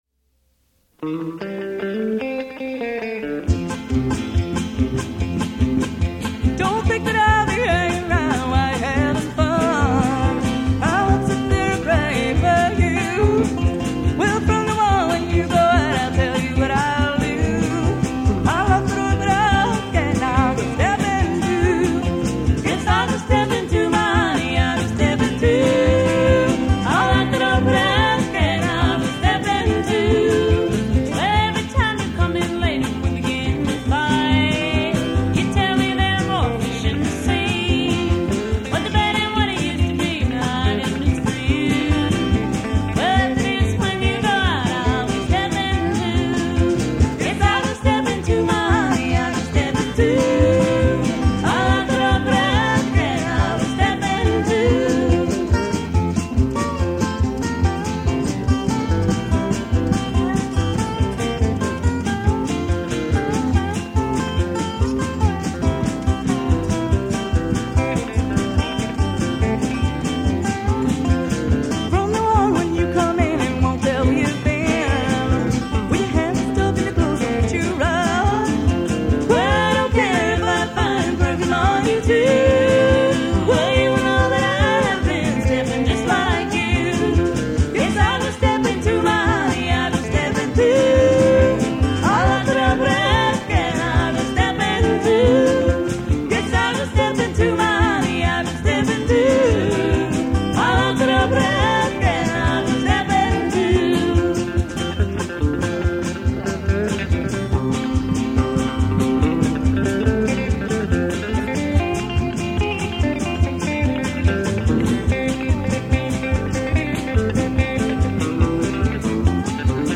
guitar
dobro
electric bass
drums